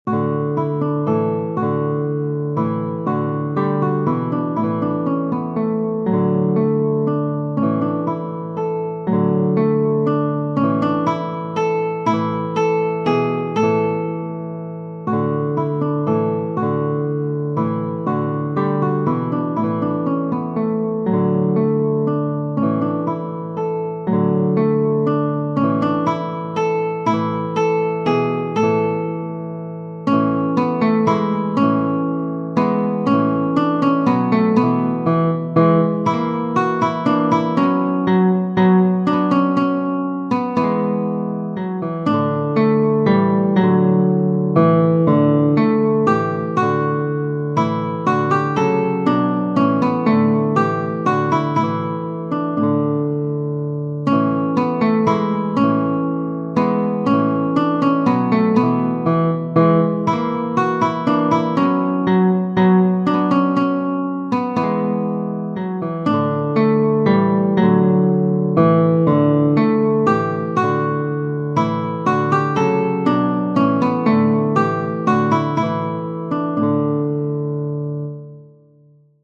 Haendel, G. F. Genere: Barocco Ispirandosi ad una danza popolare nata in Francia nel XVII secolo, Georg Friedrich Haendel compose un gran numero di minuetti, fra cui il Minuetto HWV 500 in RE maggiore. Sebbene il brano fosse destinato da Haendel ad essere eseguito su tastiera, ritengo valida la possibilità di arrangiarlo per un duetto di chitarre.